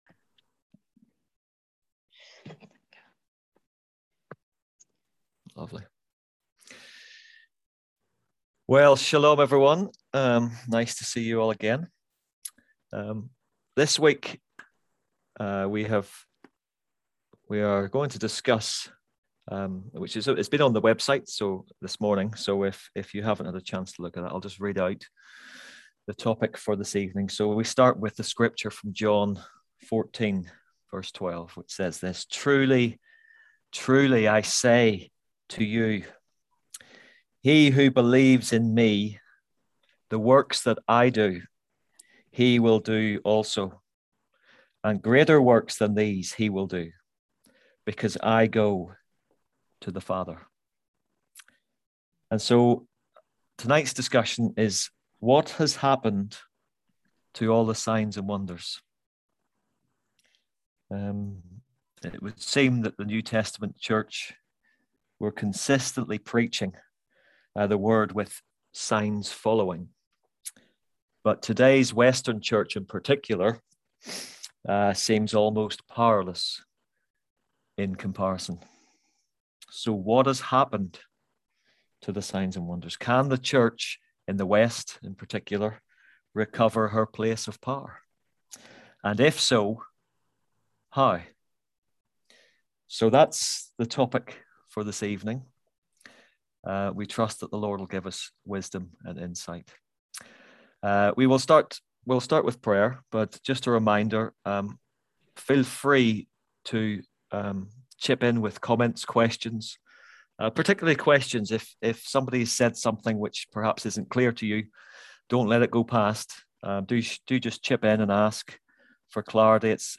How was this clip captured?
Click here to listen to the full story of our F14 conference, ‘when Christians disagree’.